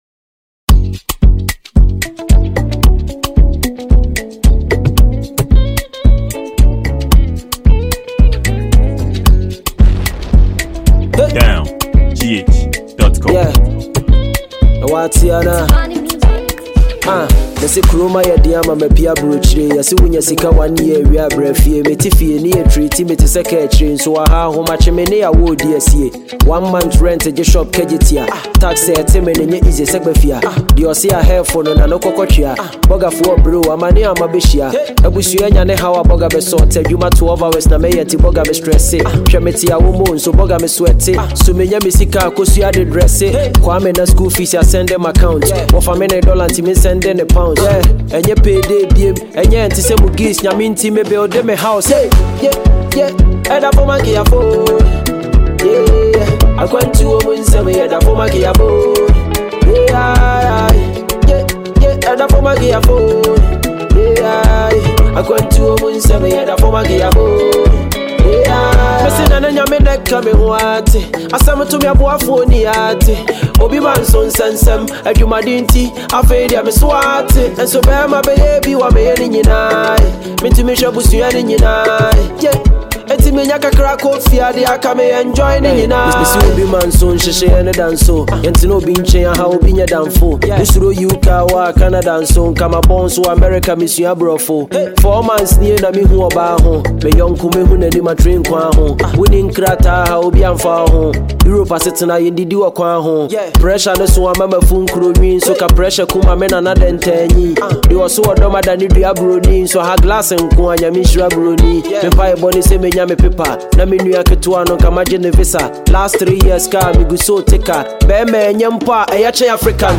a free mp3 download music by Ghanaian rapper